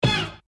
Spray 4
spray-4.mp3